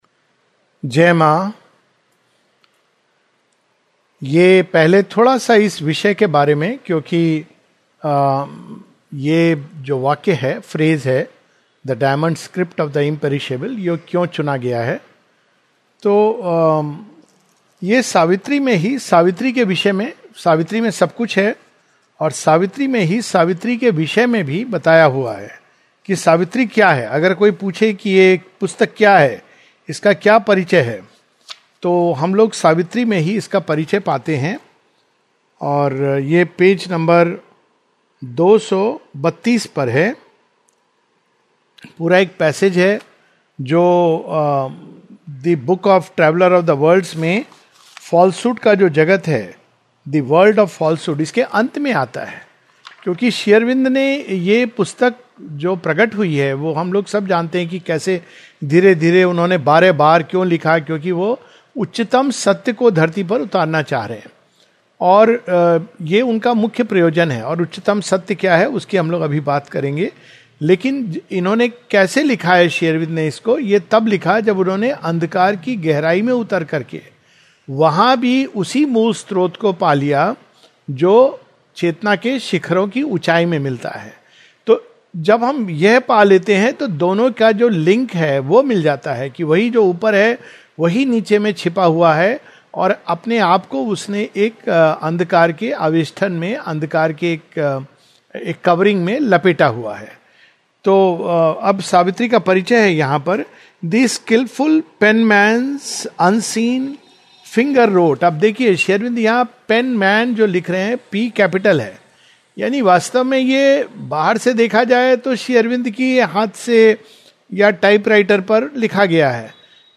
This is a Webinar with the Hindi Zone of the Sri Aurobindo Society where the different aspects of Faith as a fundamental necessity is discussed, following the September issue of the Agnishikha magazine.